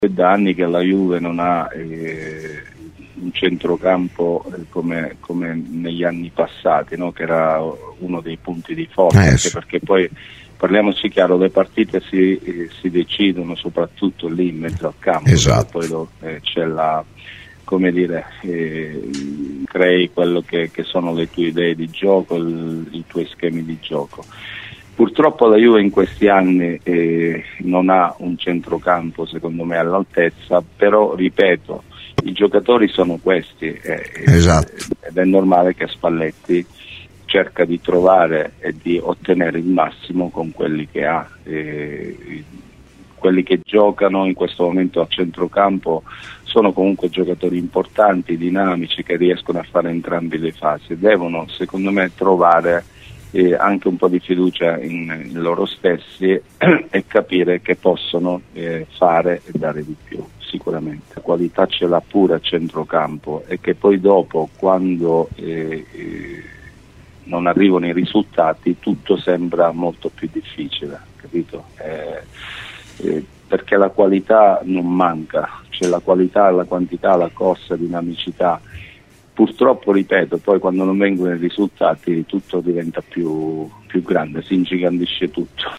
su Radio Bianconera